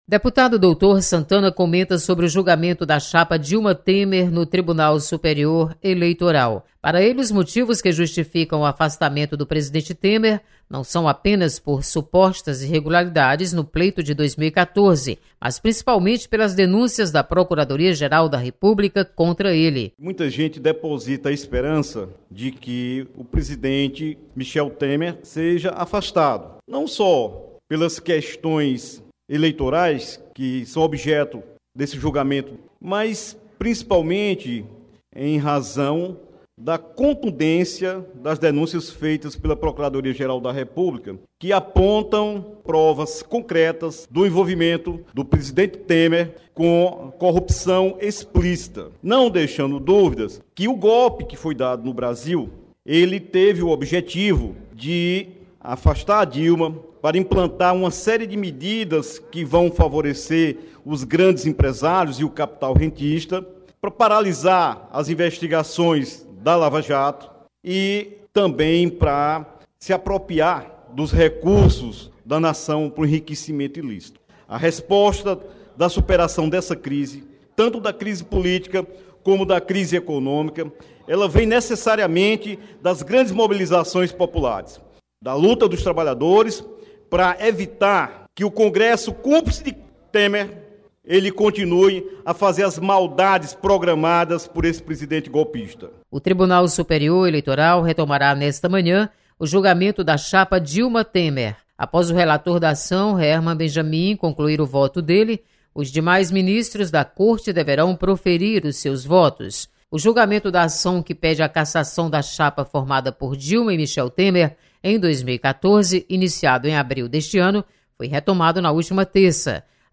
Julgamento da chapa Dilma/Temer volta a ser comentado na Assembleia Legislativa. Repórter